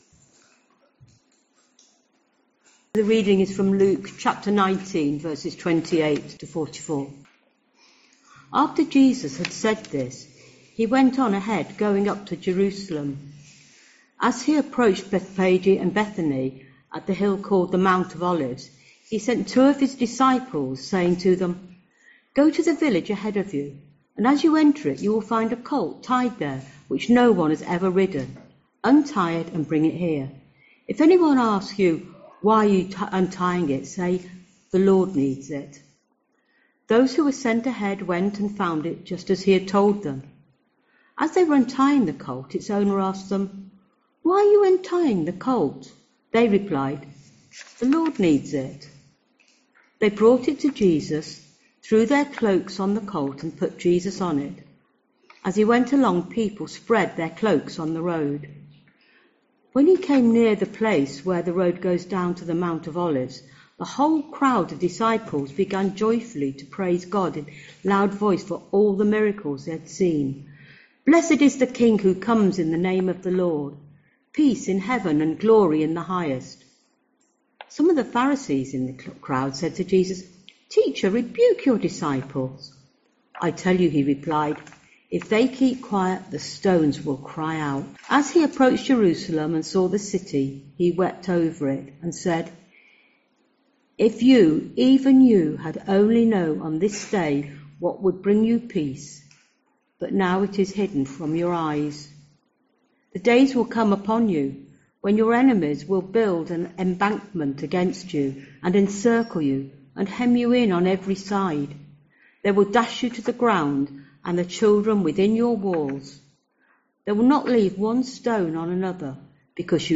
Sermons - Swanfield Chapel
Swanfield Chapel is an Evangelical Church in Chichester, West Sussex, England.